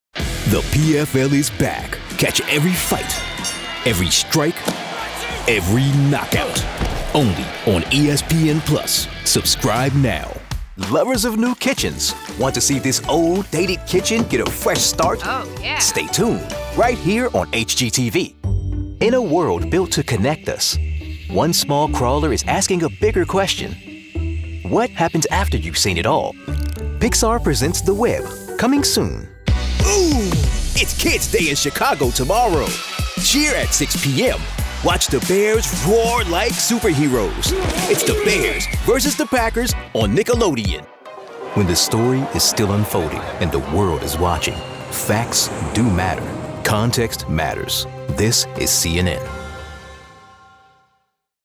English (American)
Promos
Sennheiser 416, Neumann U87, TLM 103